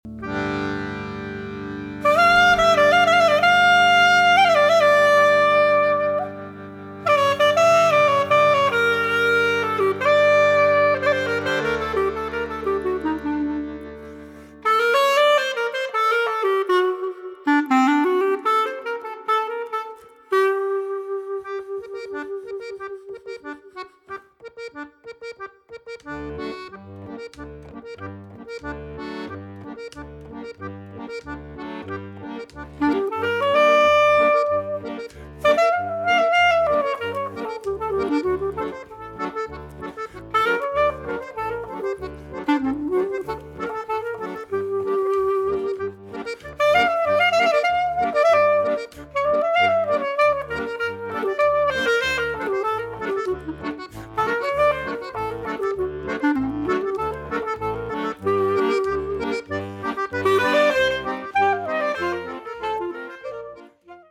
Bassgitarren